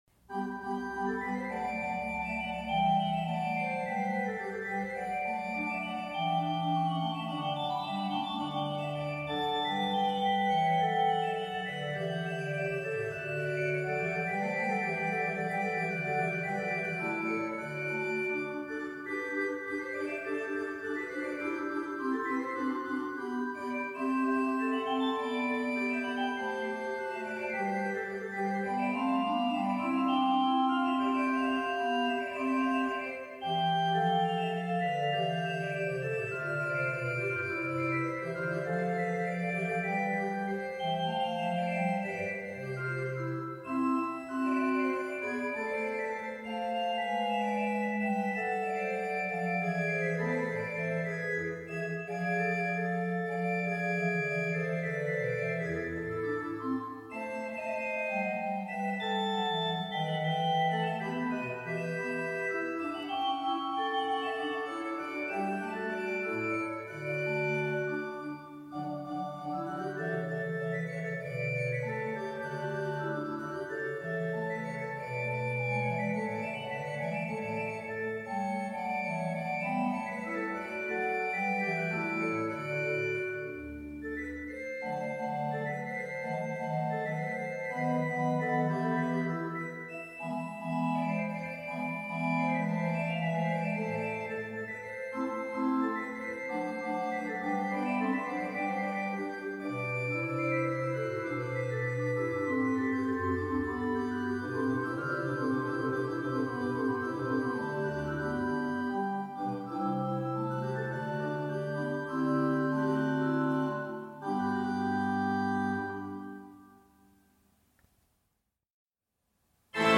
Die Oberlinger-Orgel der ev. Kirche Winningen - Prelude Orgelinfo
spielt Praeludium III in G-Dur von Georg Andreas Sorge